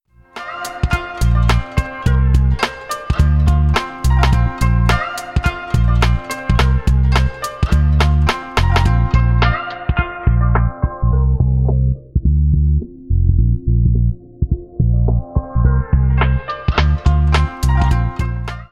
传奇的砂砾和松脆感
RX950 Classic AD / DA转换器，这是我们的第一个效果插件，旨在完美地模仿Akai S950的整个AD / DA转换过程，以VST的轻松和灵活性为您的音乐提供这种复古，温暖和松脆的声音。
许多人认为12位的砂砾和紧缩在今天仍然无法比拟。